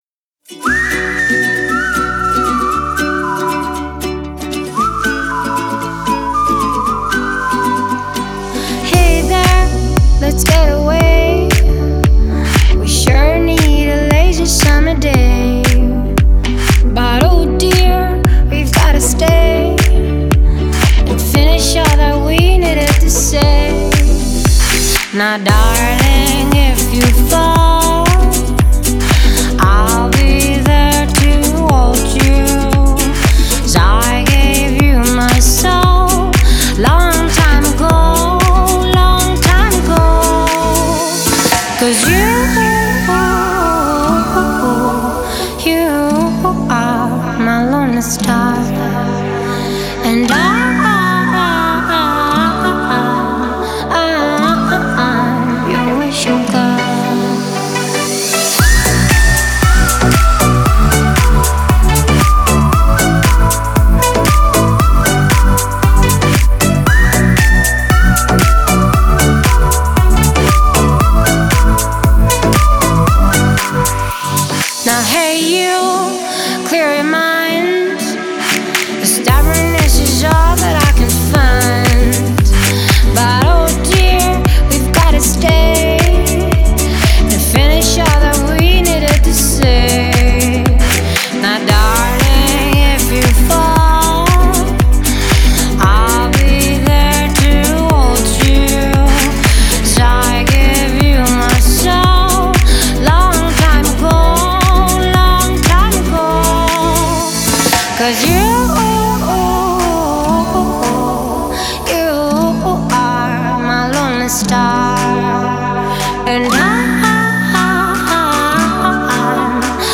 Стиль: Dance / Pop / Tropical House